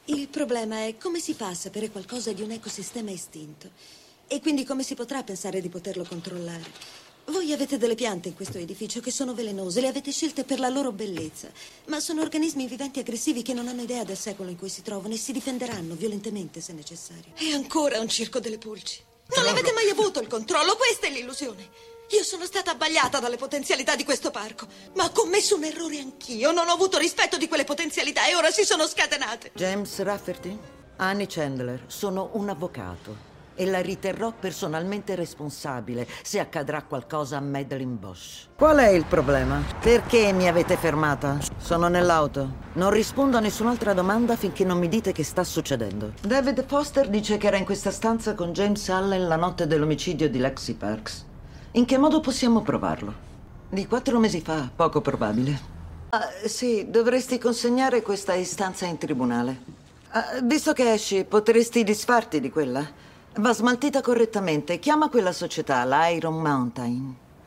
FILM CINEMA